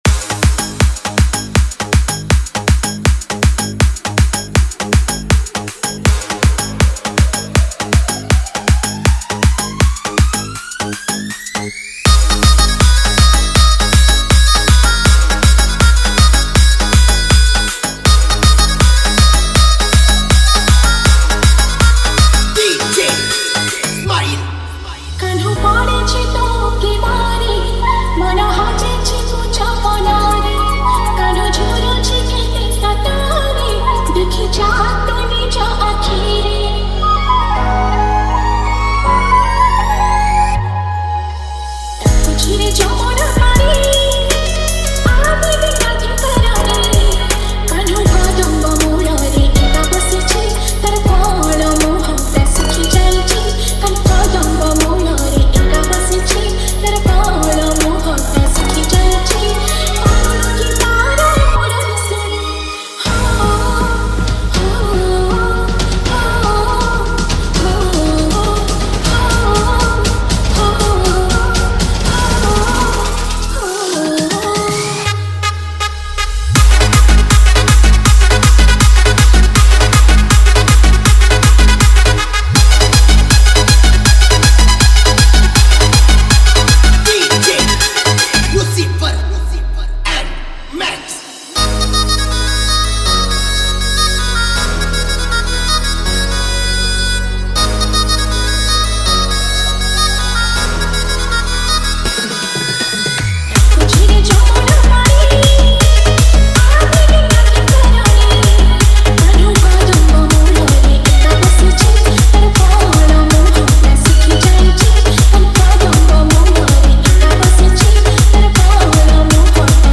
Category:  Odia Bhajan Dj 2025